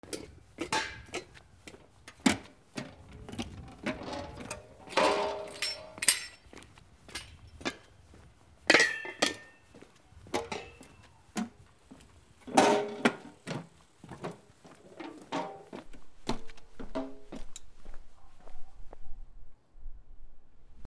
側溝のふたの上を歩く
/ I｜フォーリー(足音) / I-240 ｜足音 特殊1